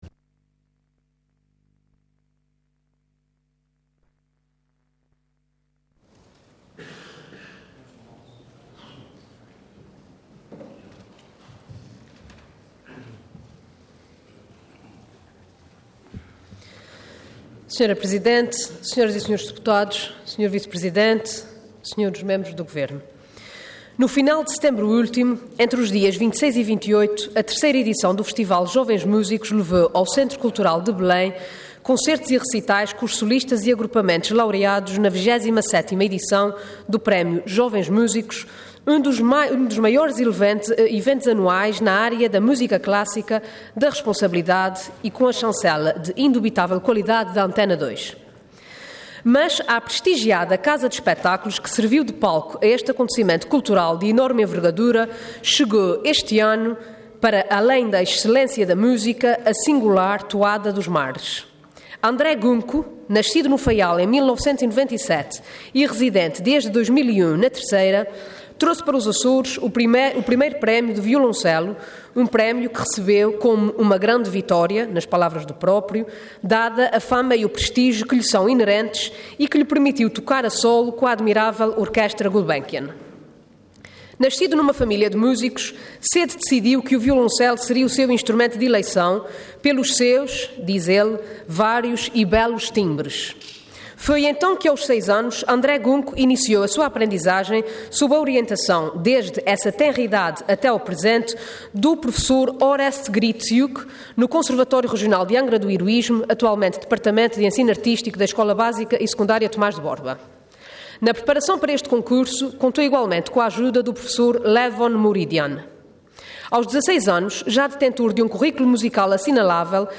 Intervenção Voto de Congratulação Orador Renata Correia Botelho Cargo Deputada Entidade PS